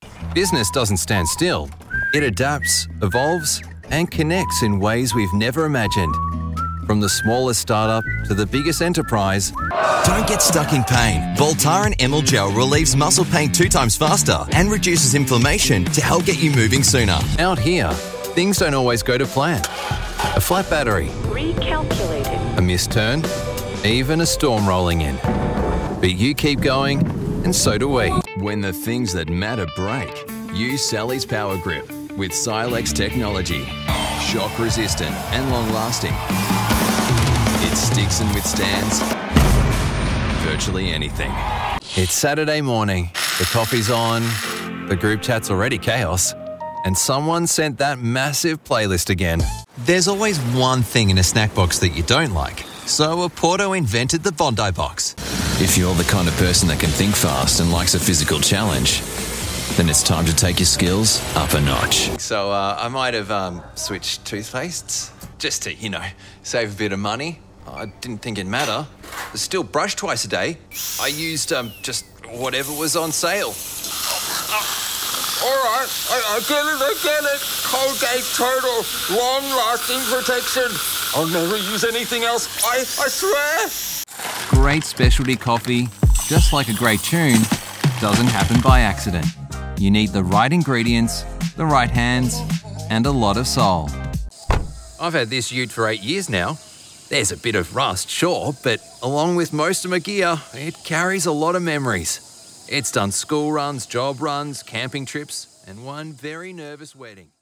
British Radio & TV Commercial Voice Overs Artists
Adult (30-50)